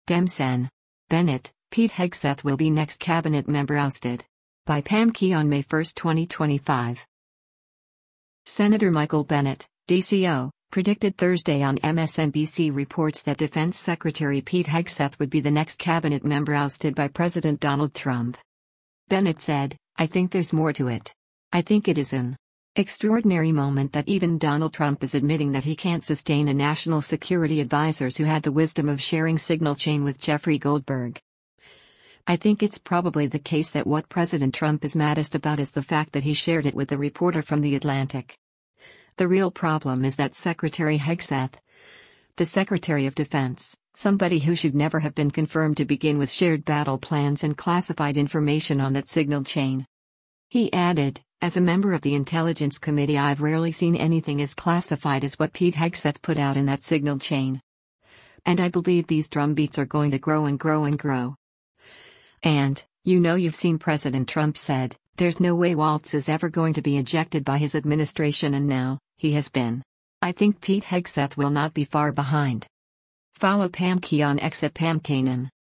Senator Michael Bennet (D-CO) predicted Thursday on “MSNBC Reports” that Defense Secretary Pete Hegseth would be the next cabinet member ousted by President Donald Trump.